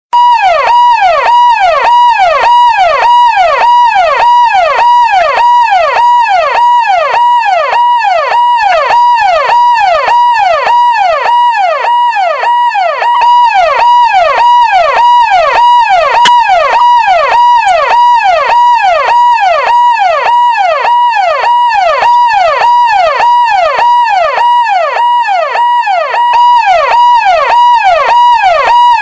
Sirens, Ambulans siren, Android